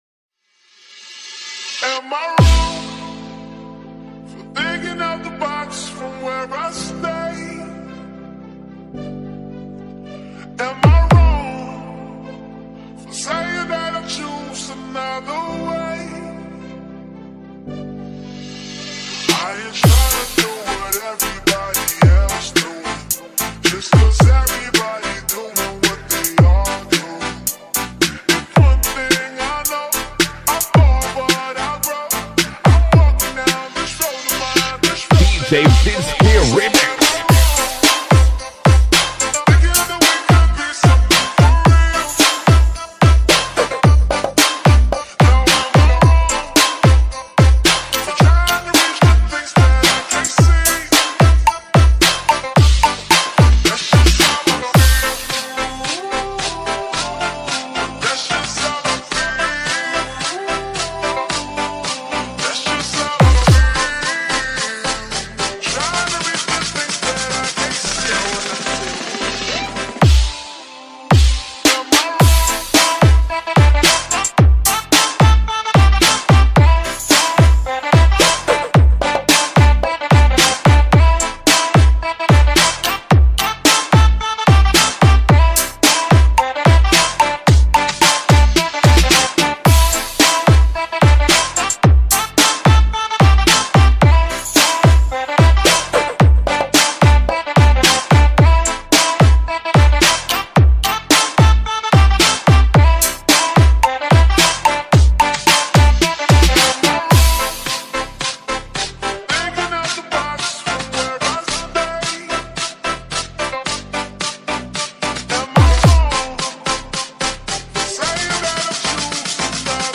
BASS BOOSTED REMIX